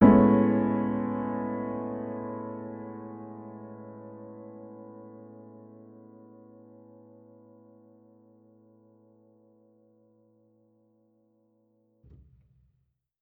Index of /musicradar/jazz-keys-samples/Chord Hits/Acoustic Piano 2
JK_AcPiano2_Chord-Amaj9.wav